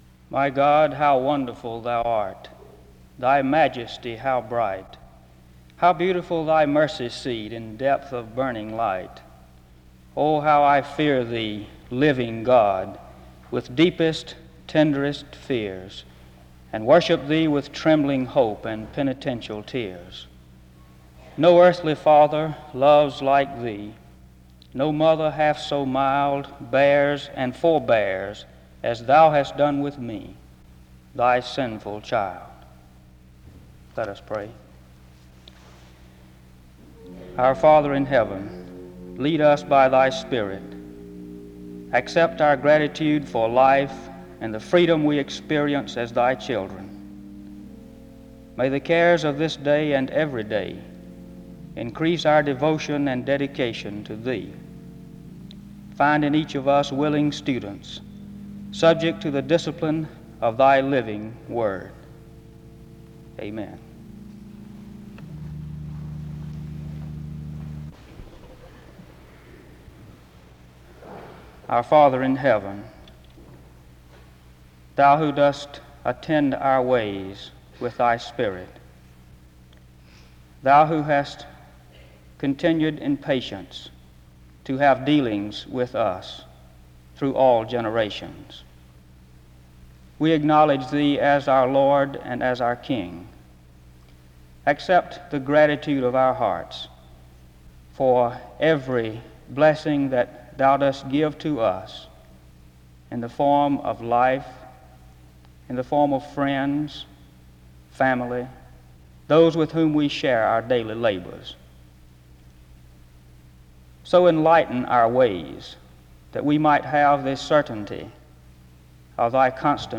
SEBTS Chapel
SEBTS Chapel and Special Event Recordings SEBTS Chapel and Special Event Recordings